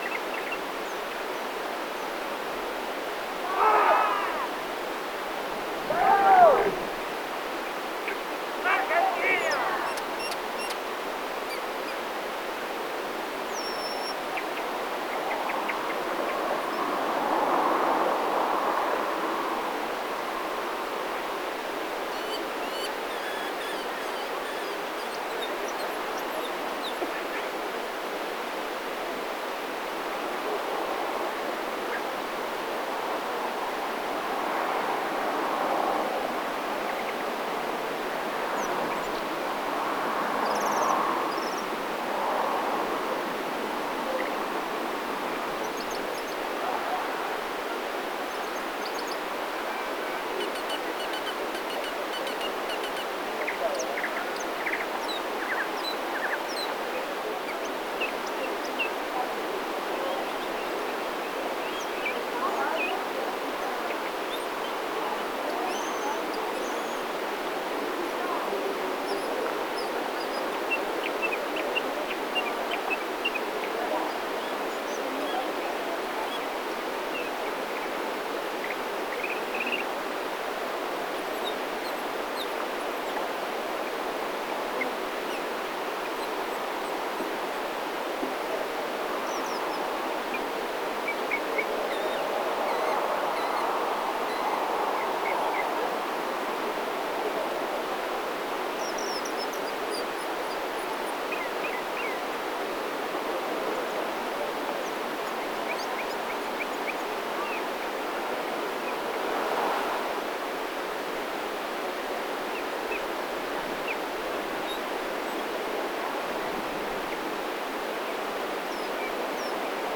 esa-la-rioja-talampaya-sendero-de-triasico.mp3